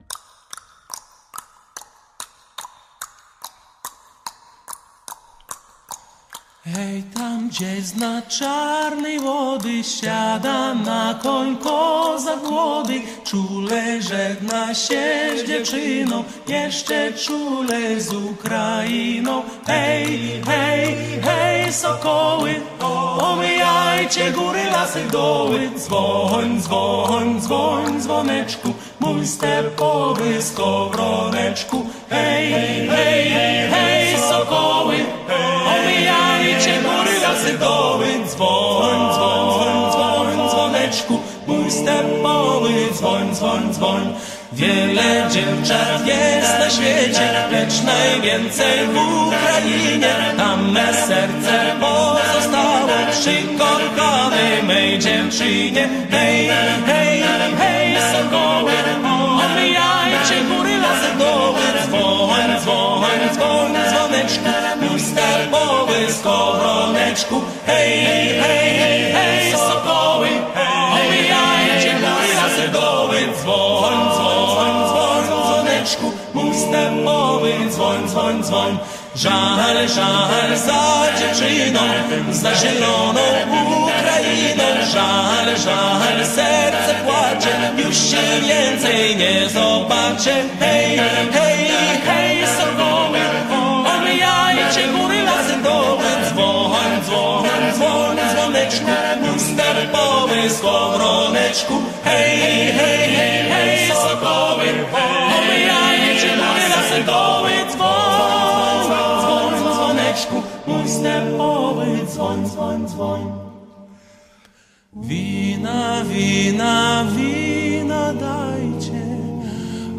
Hej, sokoły! - Polish Folk….mp3 📥 (2.82 MB)
Heard this one before, I like eastern european folk music a lot